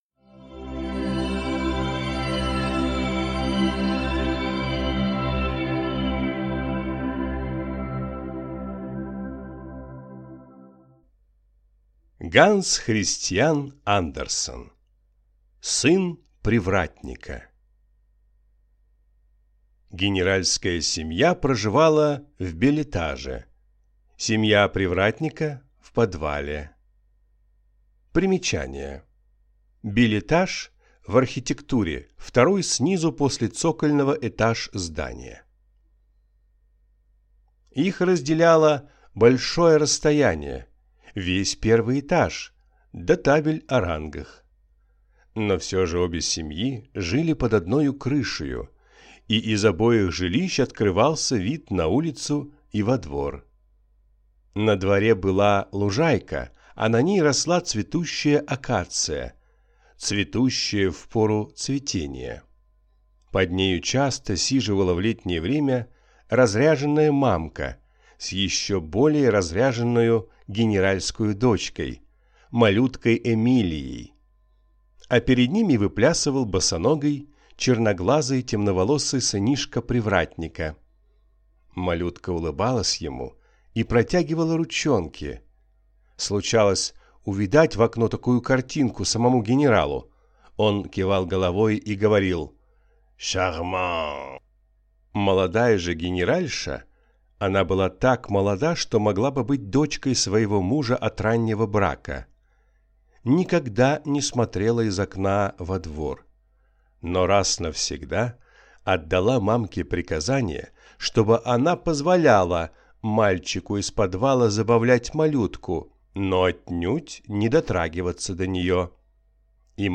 Аудиокнига Сын привратника | Библиотека аудиокниг